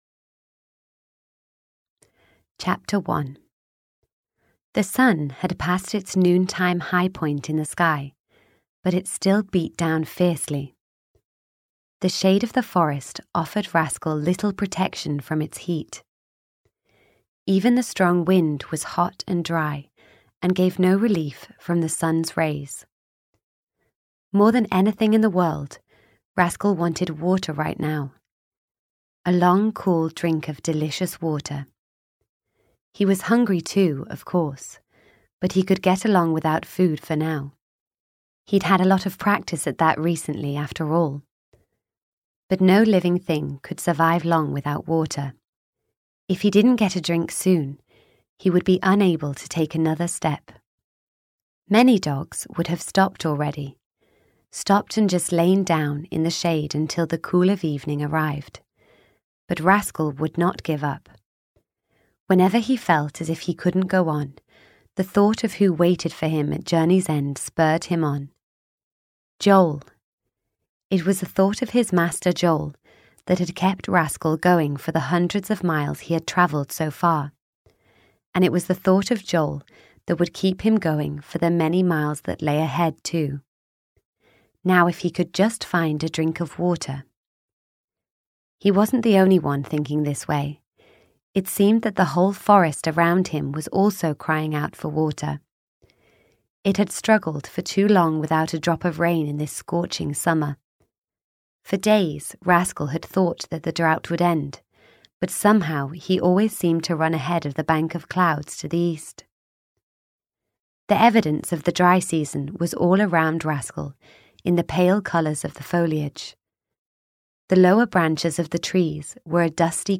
Rascal 4 - Facing the Flames (EN) audiokniha
Ukázka z knihy